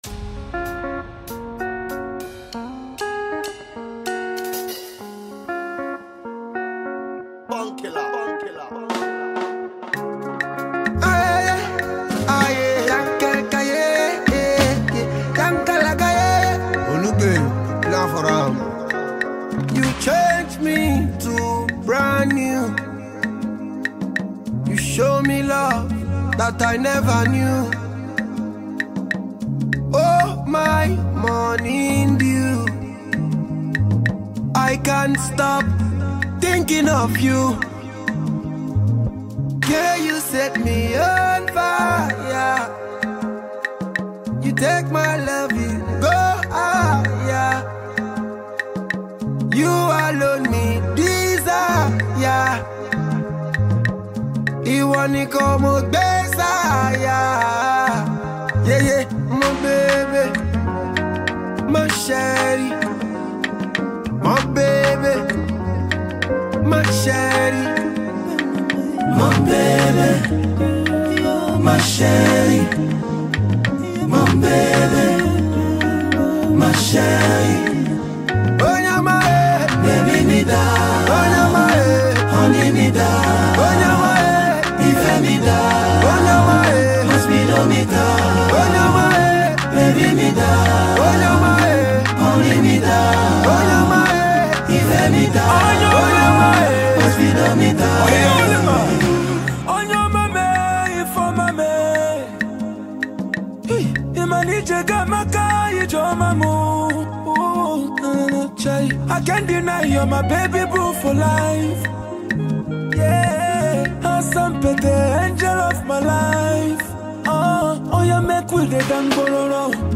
Nigerian Dance Hall veteran